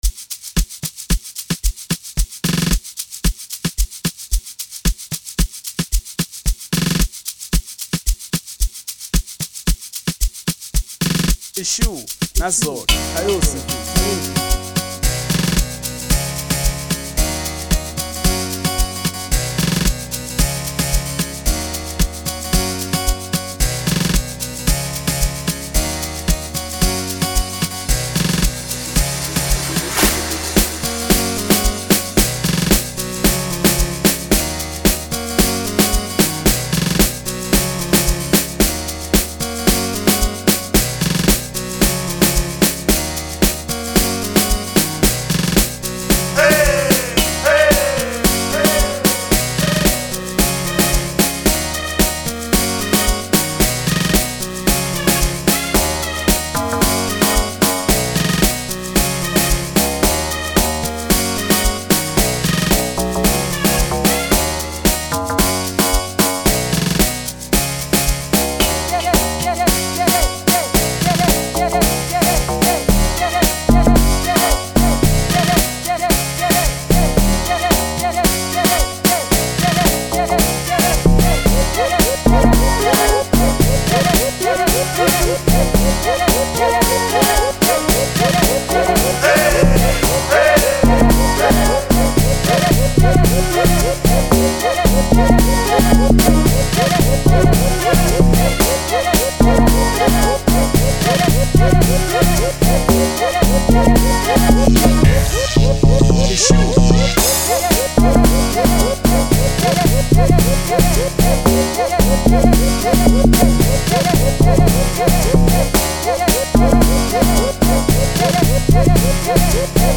03:57 Genre : Amapiano Size